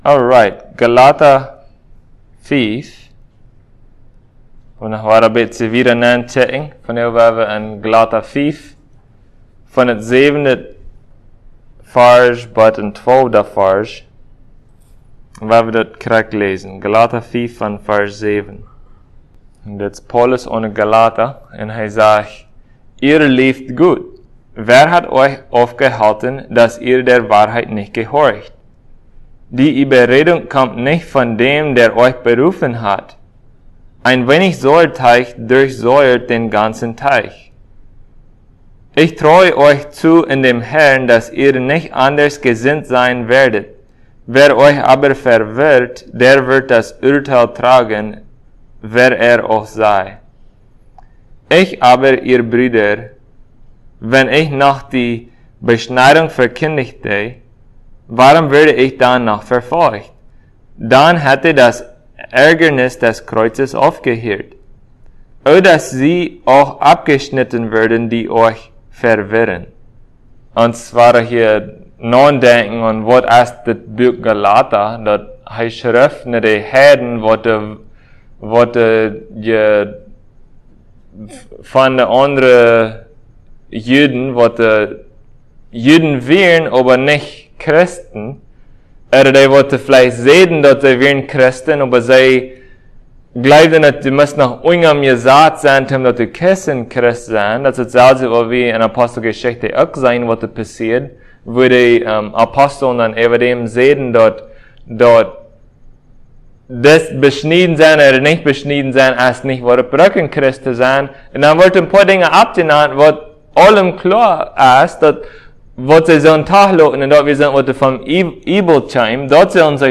Passage: Galatians 5:7-12 Service Type: Sunday Plautdietsch « Biblical Faith The Fruit of Faith